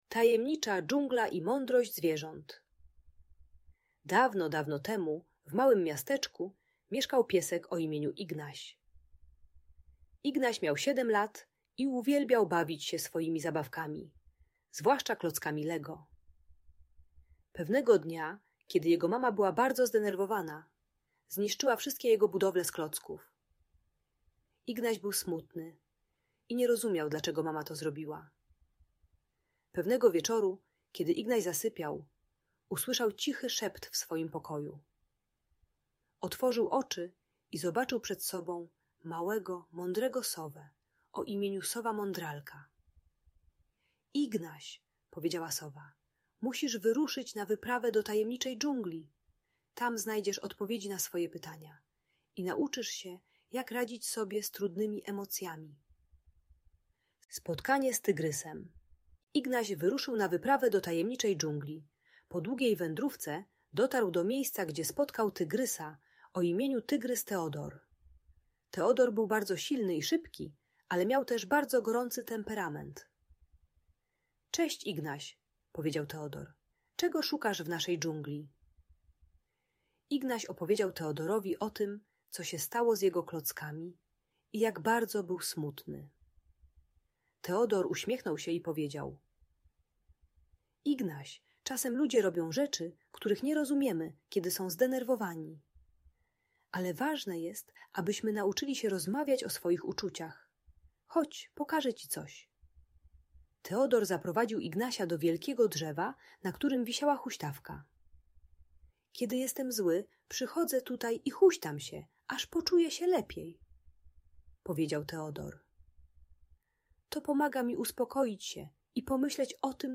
Tajemnicza Dżungla - Lęk wycofanie | Audiobajka